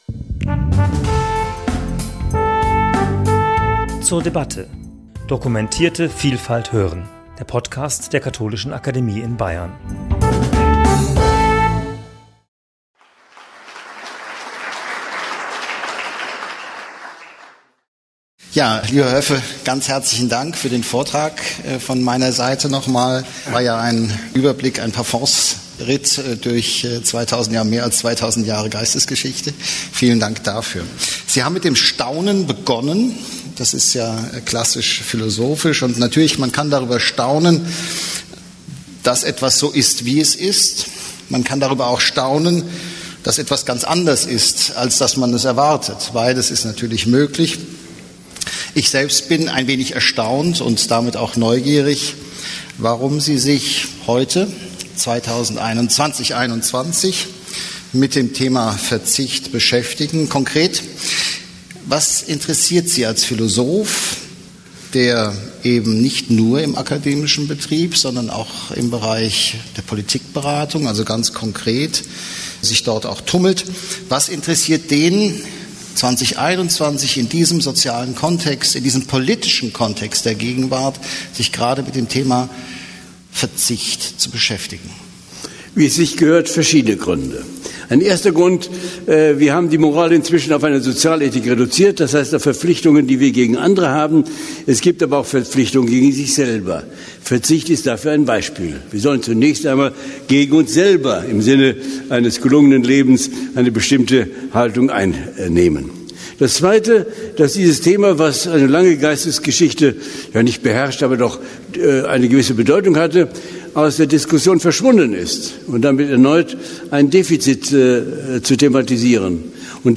Gespräch zum Thema 'Wozu Verzicht?' ~ zur debatte Podcast